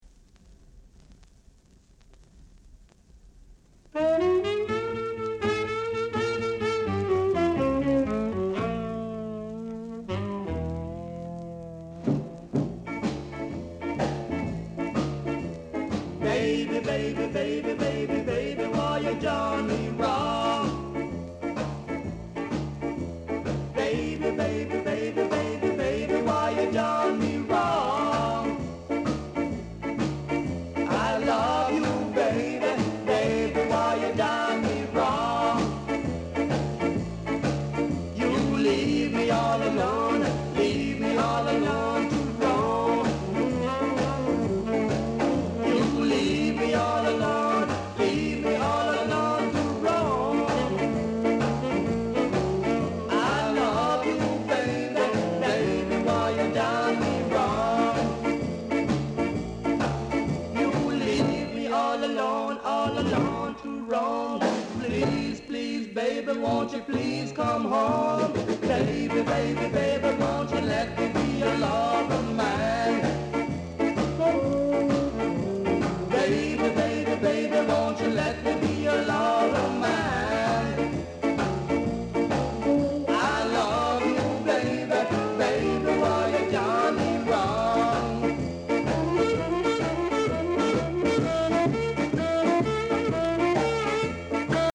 Ska Male Vocal
nice Ja r&b vocal w-sider!